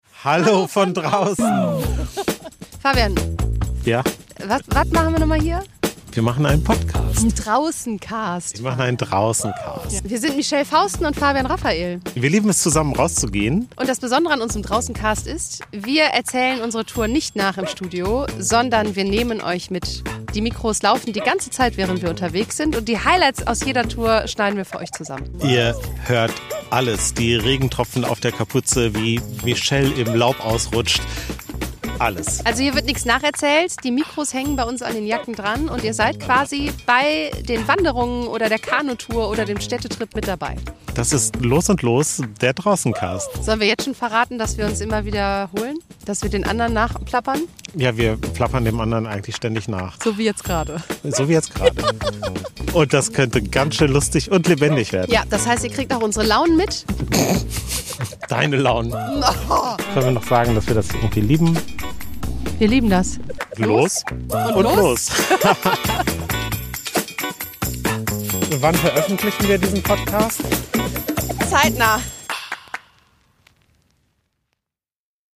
sind kein Studiopodcast, alles was auf unserem Kanal passiert ist